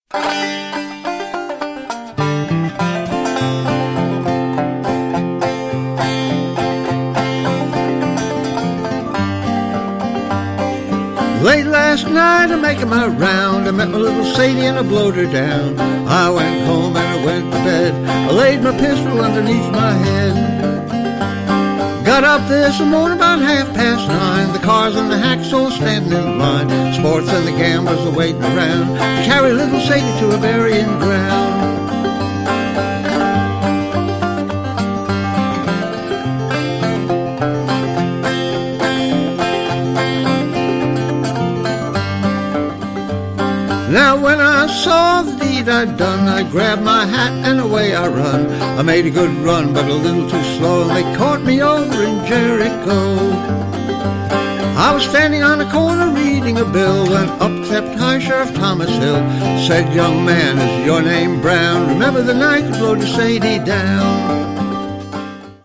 guitar, banjo and fiddle
classy fiddling